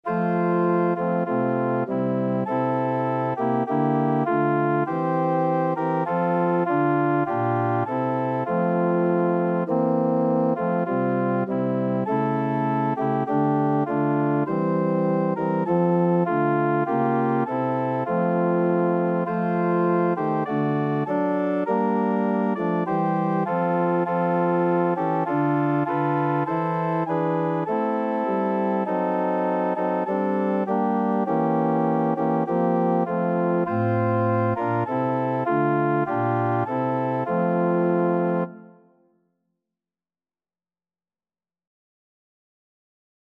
Classical Trad. Round the Lord in Glory Seated Organ version
F major (Sounding Pitch) (View more F major Music for Organ )
4/4 (View more 4/4 Music)
Instrument:
Christian (View more Christian Organ Music)
round_the_lord_ORG.mp3